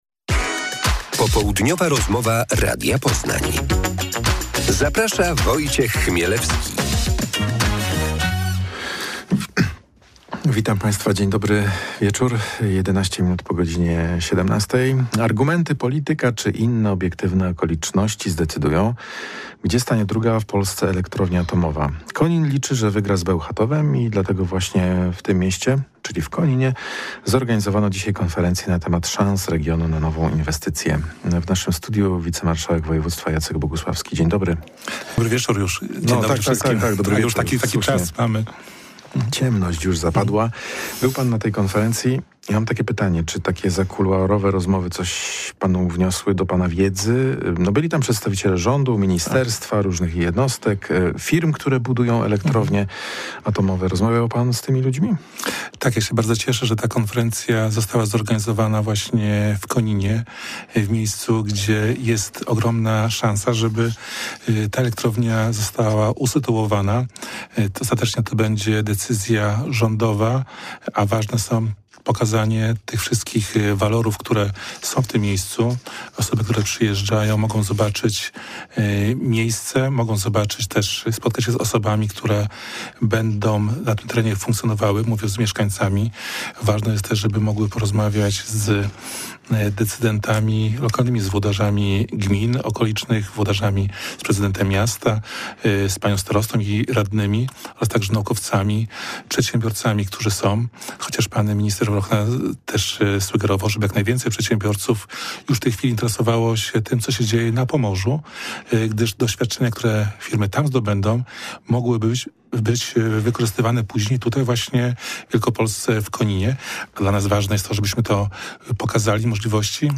Popołudniowa rozmowa Radia Poznań – Jacek Bogusławski